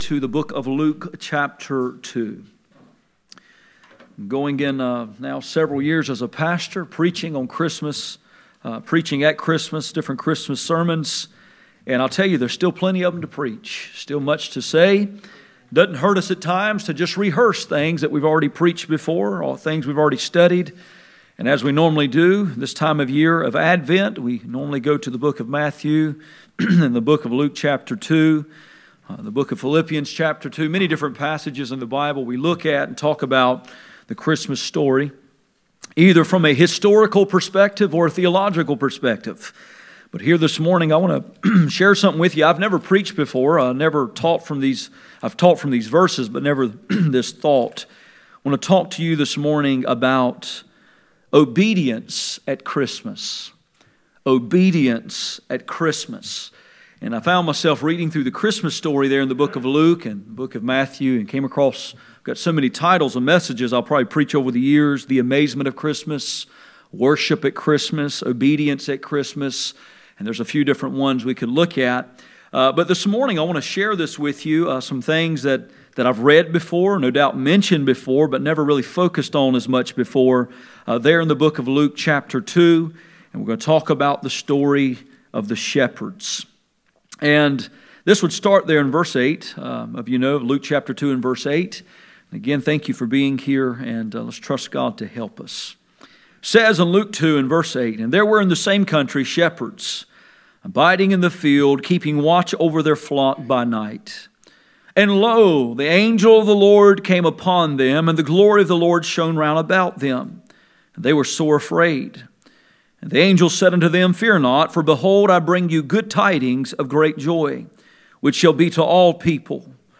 Passage: Luke 2:8-20 Service Type: Sunday Morning